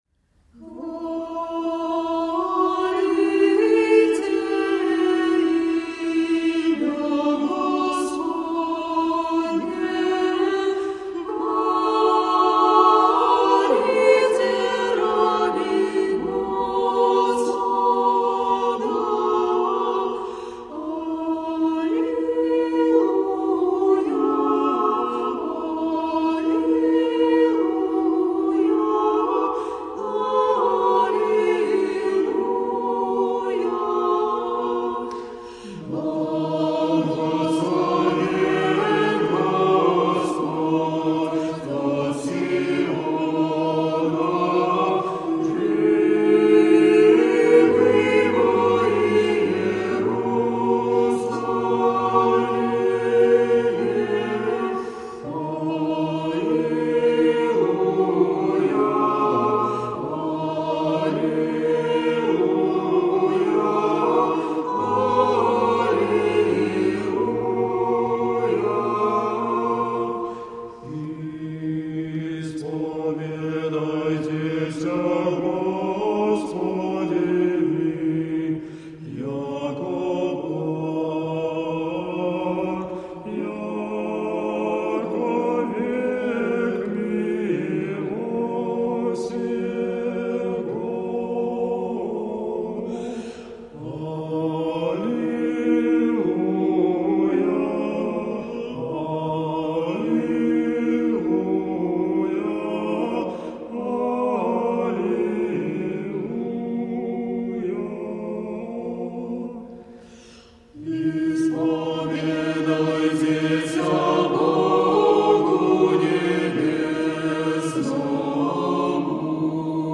Clear calm voices of spiritual music.
Tagged as: Classical, Medieval, Classical Singing, Choral